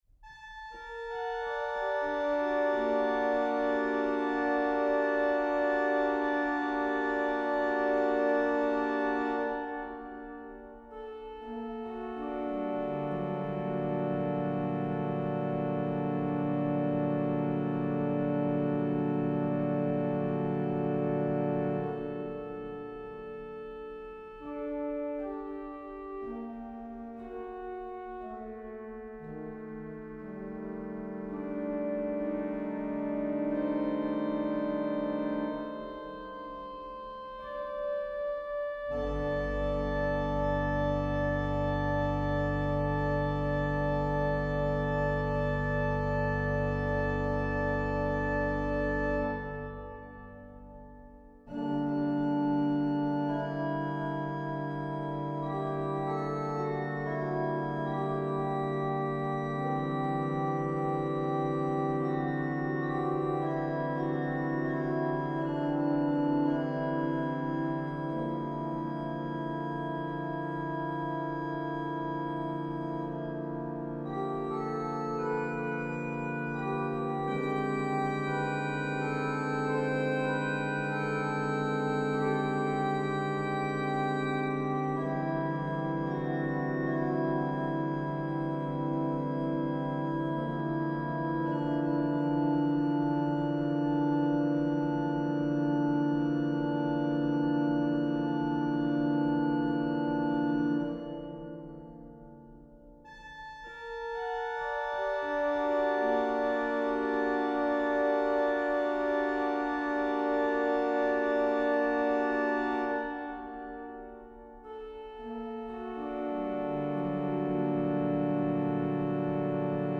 organ Click to listen.